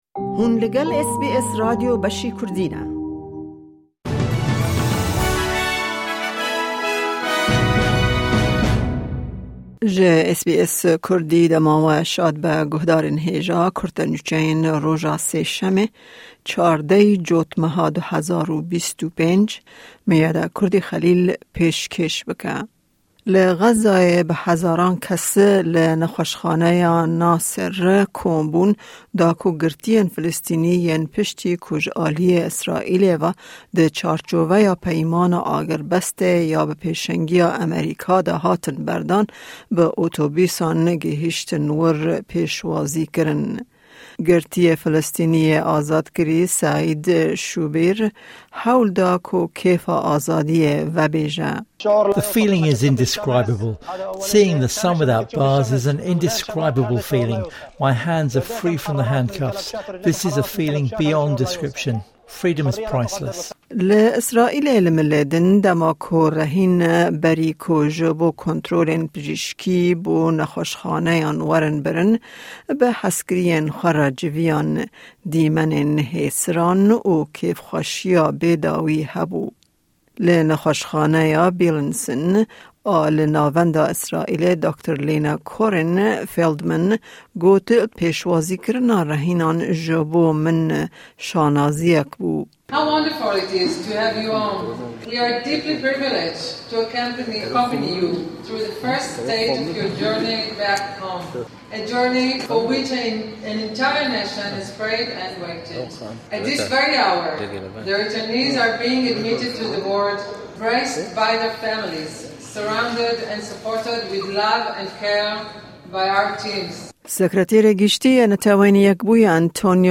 Nûçe